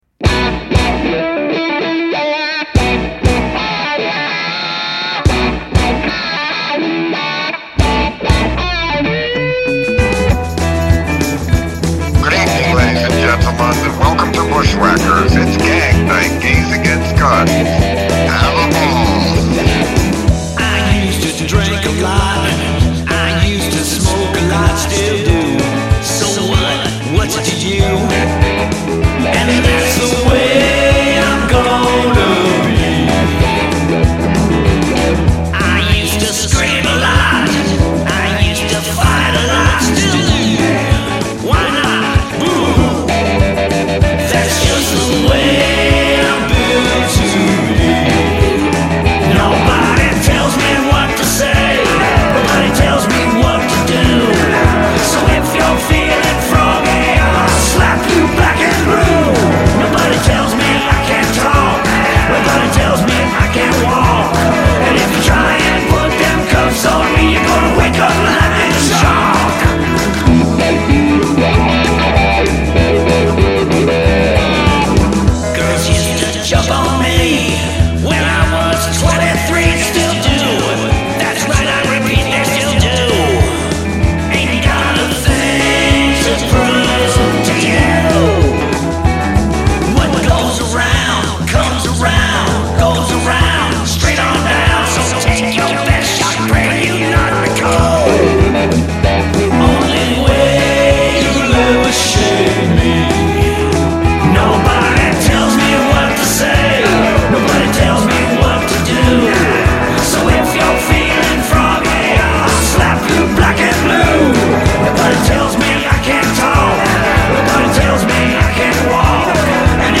theatrical rock